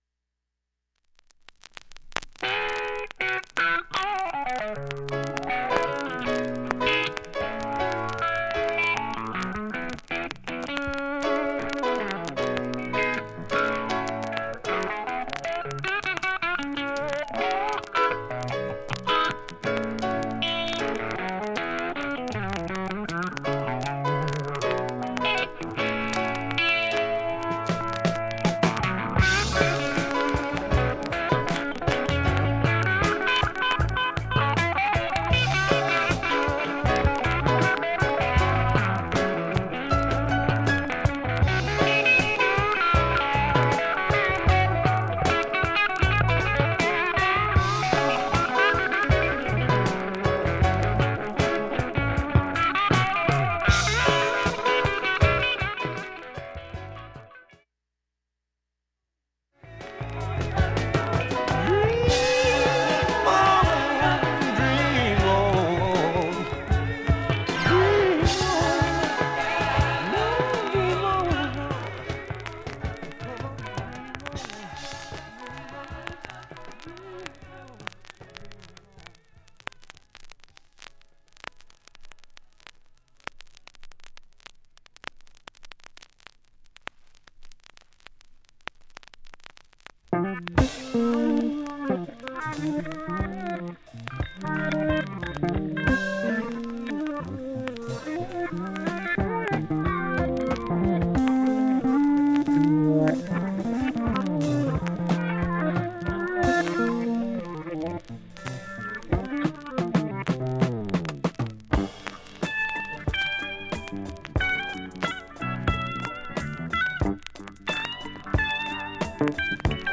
SHURE M 44G 針圧３グラムで 針飛びはありませんでした。
瑕疵部分 1,ダイジェスト試聴入れました5:39秒 演奏中はストレスはありません。